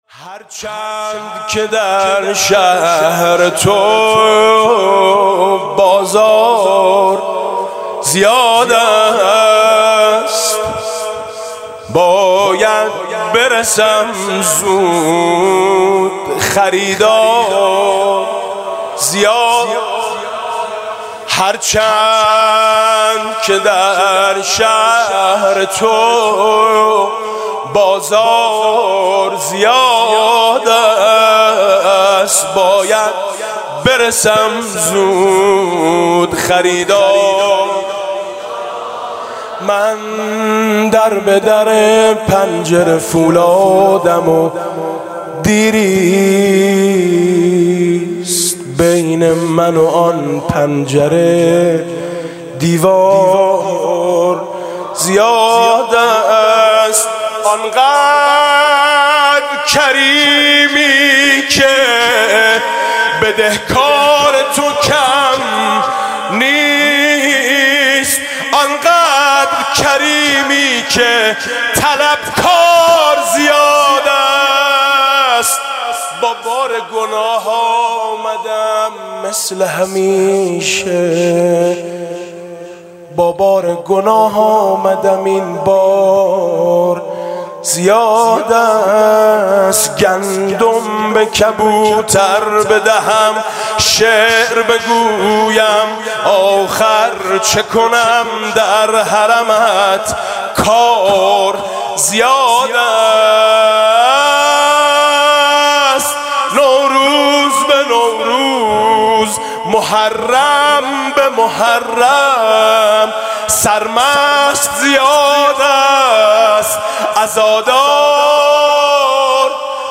«میلاد امام رضا 1394» مدح : من در به در پنجره فولادم